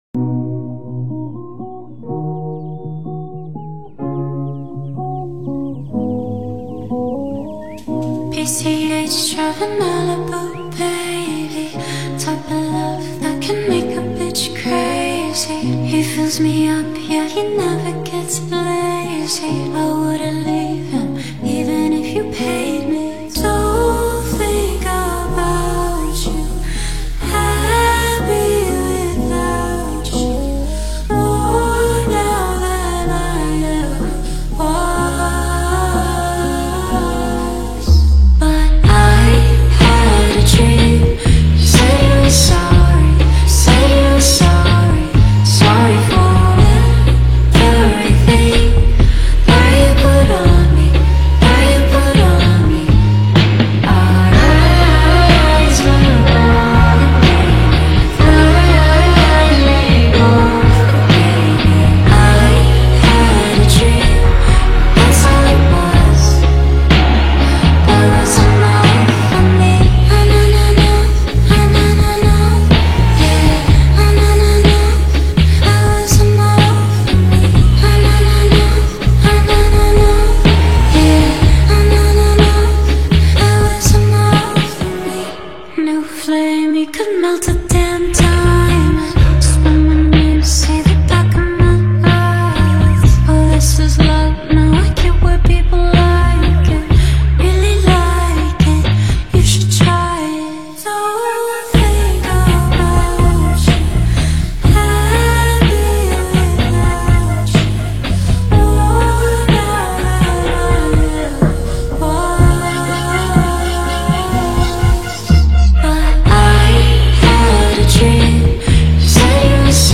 در سبک پاپ خونده شده